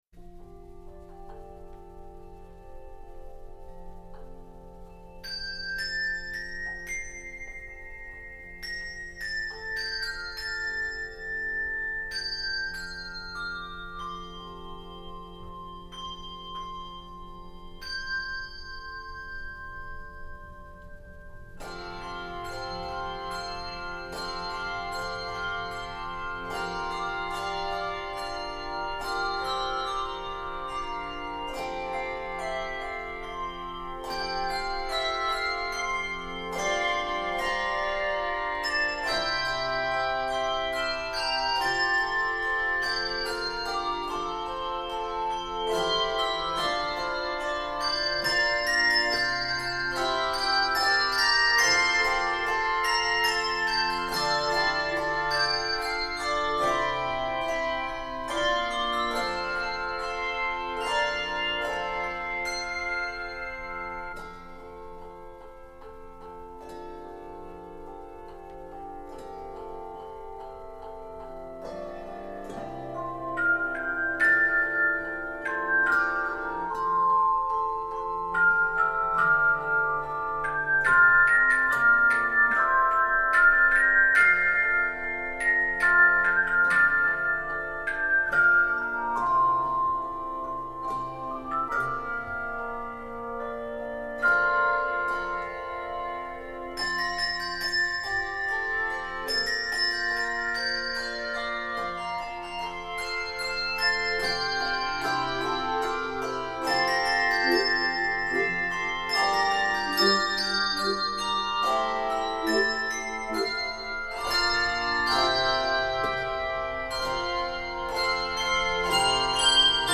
3-6 octave handbells
optional 3 or 5 octave handchimes